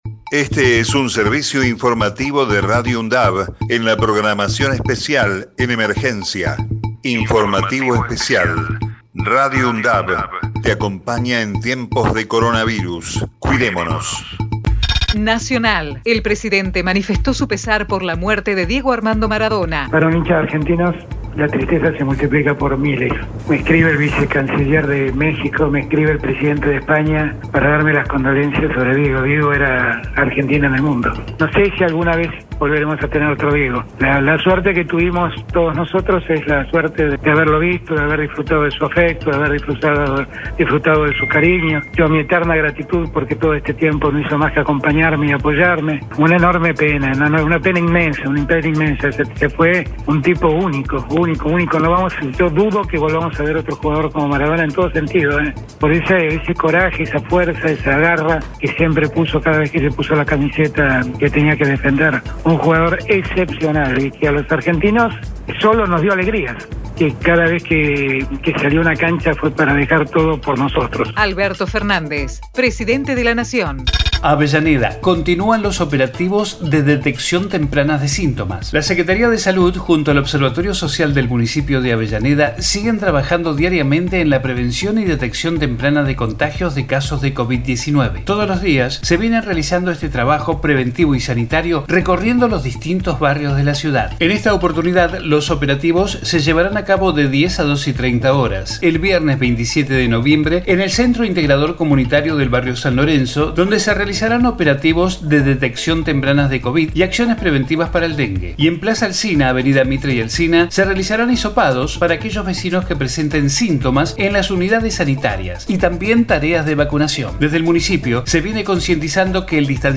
COVID-19 Informativo en emergencia 26 de noviembre 2020 Texto de la nota: Este es un servicio informativo de Radio UNDAV en la programación especial en emergencia.